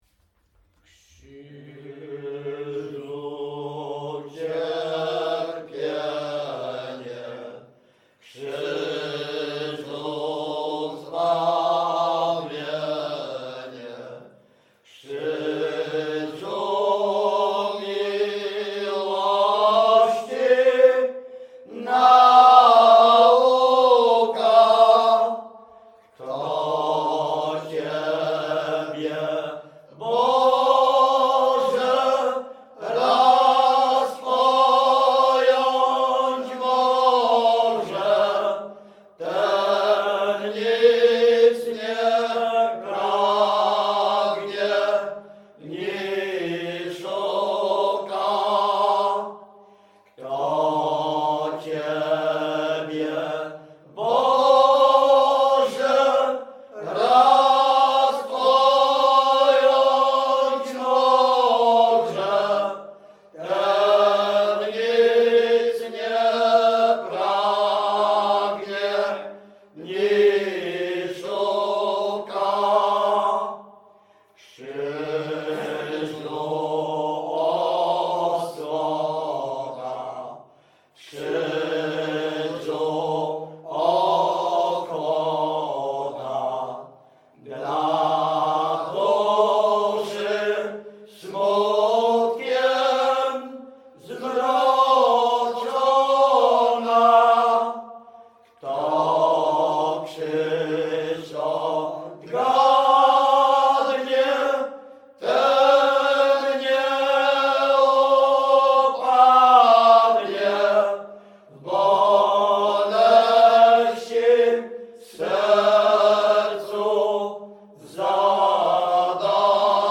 Śpiewacy z Ruszkowa Pierwszego
Wielkopolska, powiat kolski, gmina Kościelec, wieś Ruszków Pierwszy
śpiewają ze sobą od młodych lat, mają bardzo bogaty repertuar pieśni (zwłaszcza religijnych) w miejscowych wariantach melodycznych
Nabożna
Array nabożne katolickie pogrzebowe wielkopostne